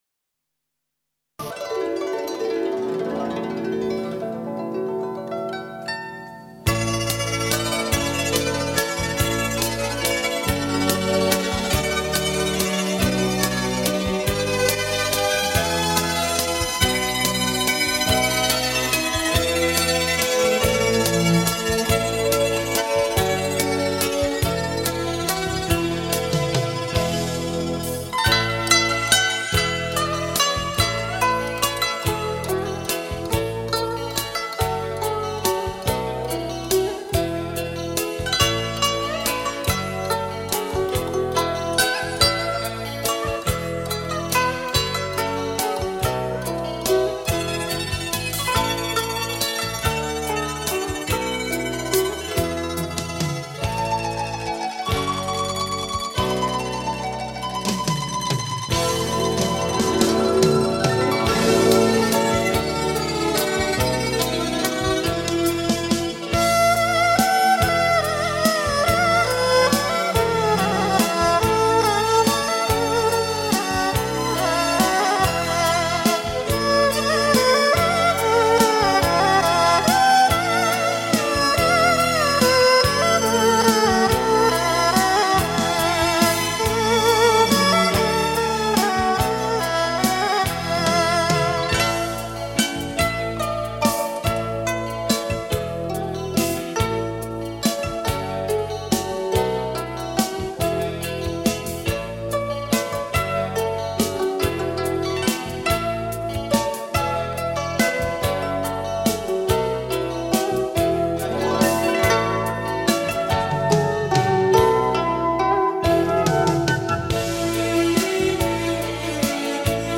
二胡